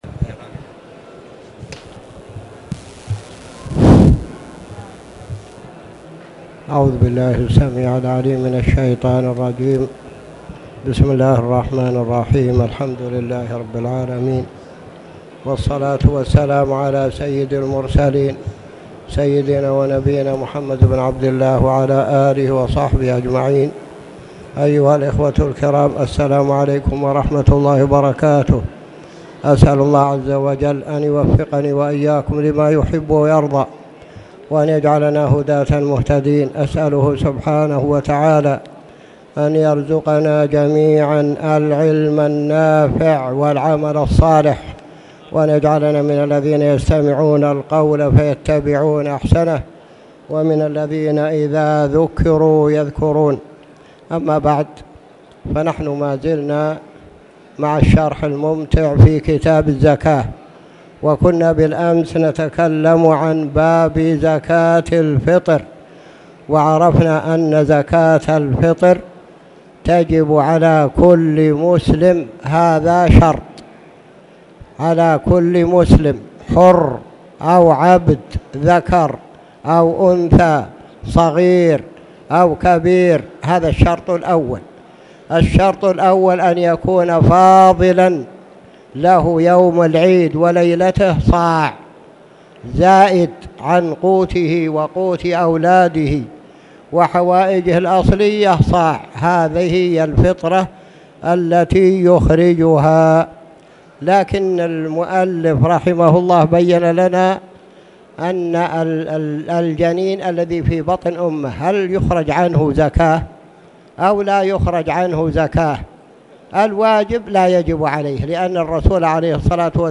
تاريخ النشر ٢٧ جمادى الآخرة ١٤٣٨ هـ المكان: المسجد الحرام الشيخ